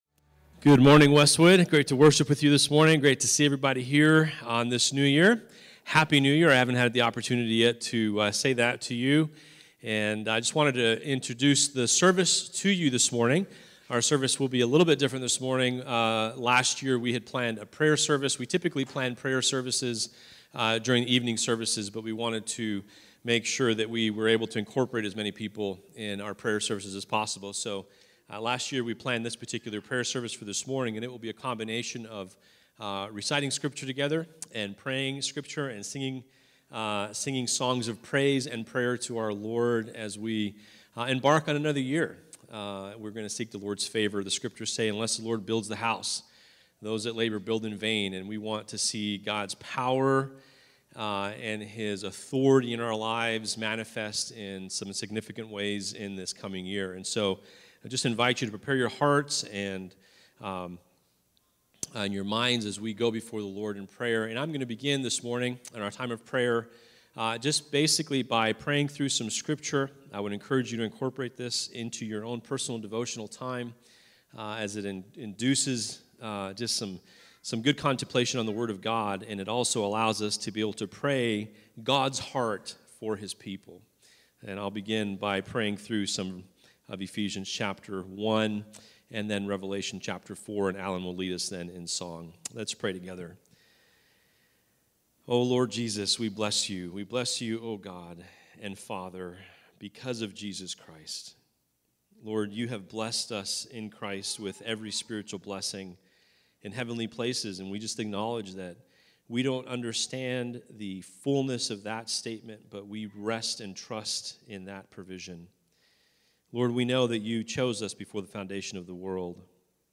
Message: “Prayer Service” from Various Speakers